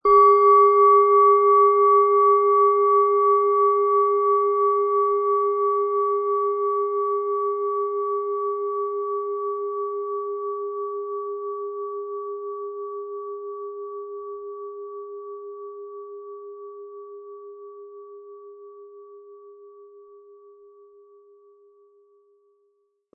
Tibetische Herz-Bauch-Kopf- und Fuss-Klangschale, Ø 13,7 cm, 320-400 Gramm, mit Klöppel
Im Audio-Player - Jetzt reinhören hören Sie genau den Original-Ton der angebotenen Schale.
Durch die traditionsreiche Herstellung hat die Schale stattdessen diesen einmaligen Ton und das besondere, bewegende Schwingen der traditionellen Handarbeit.